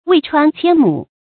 渭川千畝 注音： ㄨㄟˋ ㄔㄨㄢ ㄑㄧㄢ ㄇㄨˇ 讀音讀法： 意思解釋： 用以言竹之繁茂。